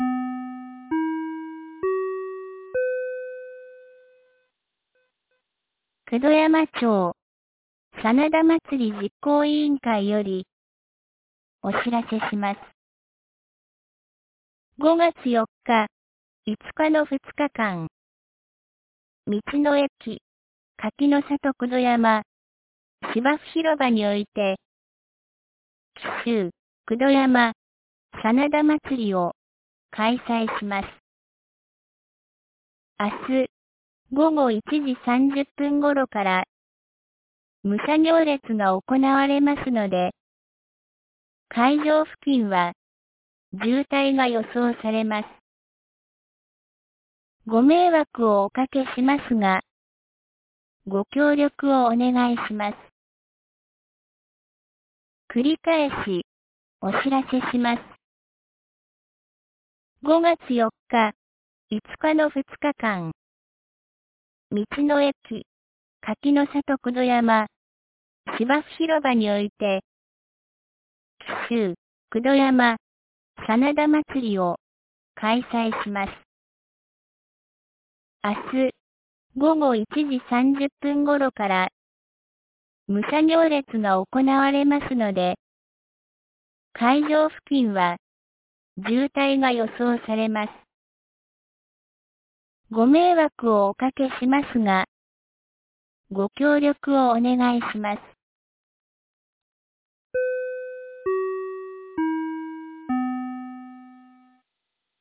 防災行政無線」カテゴリーアーカイブ
2025年05月04日 09時01分に、九度山町より全地区へ放送がありました。